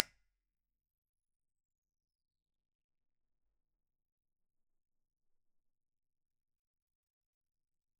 Le microphone utilisé est un Behringer ECM8000.
Mesure de la réponse impulsionnelle
La réponse impulsionnelle du haut-parleur est réalisée grâce à REAPER.
Le haut-parleur du Laney est directement connecté sur ce dernier.
cabinet_impulse_response.wav